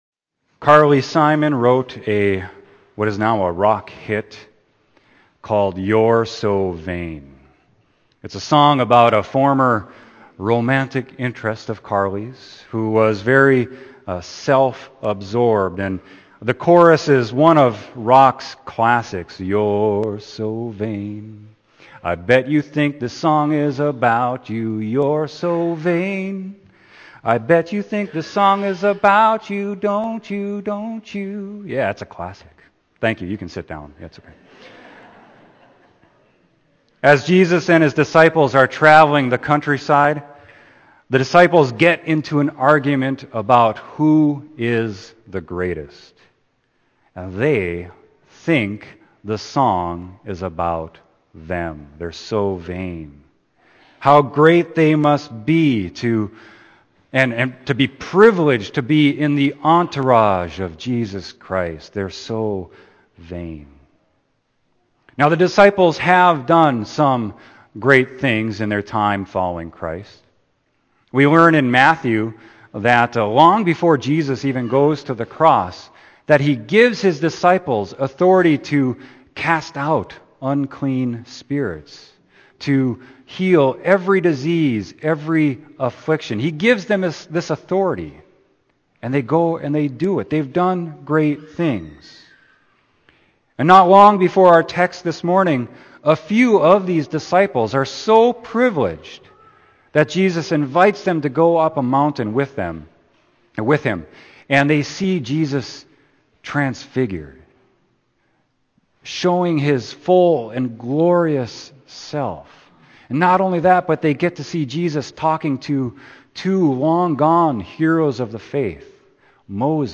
Sermon: Mark 9.30-37